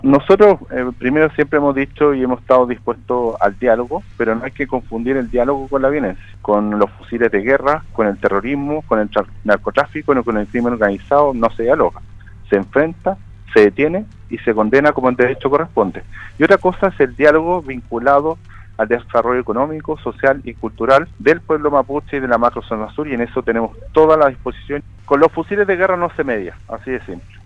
En conversación con Radio Sago, el Delegado presidencial de la Macrozona Sur, Pablo Urquizar, se refirió a la extensión del estado de excepción en cuatro provincias de las regiones del Biobío y La Araucanía, tras aprobarse en el Congreso la permanencia de esta medida en la zona.